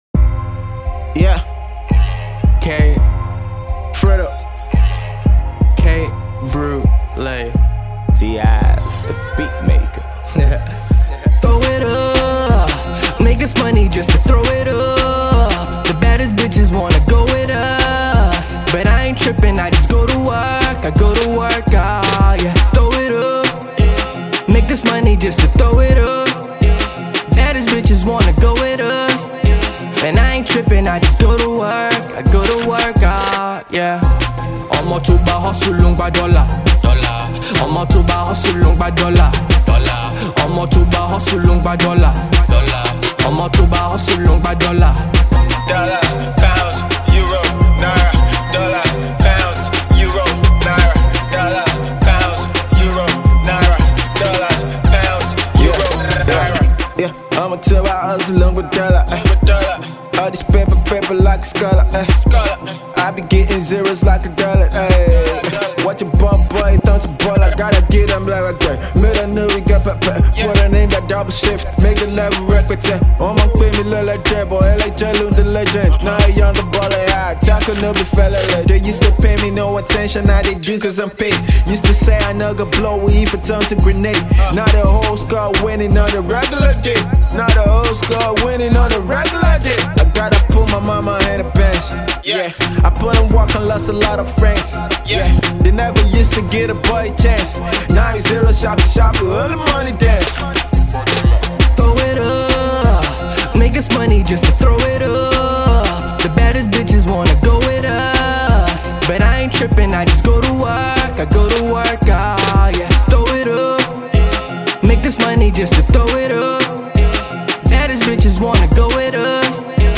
sonorous voice
trap infused rap style